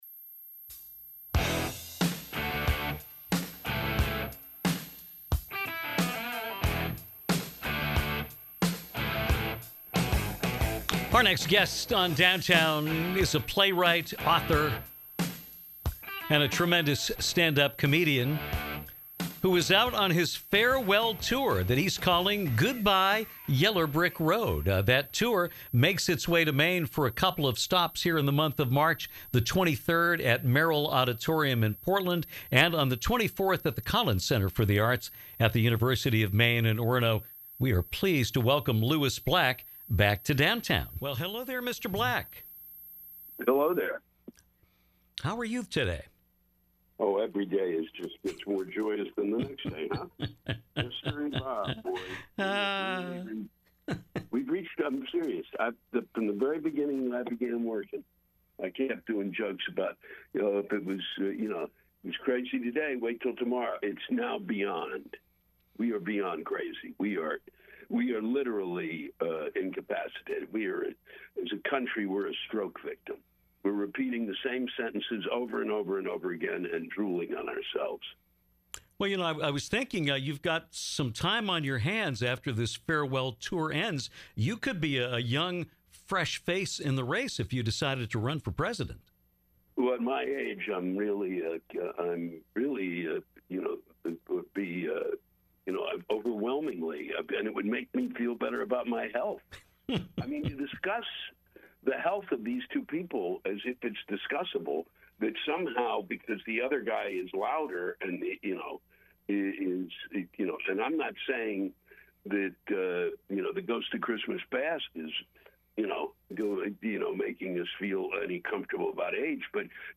Comedian Lewis Black returned to the show to talk about his farewell tour, GOODBYE YELLER BRICK ROAD, which brings him to Maine on March 23-24.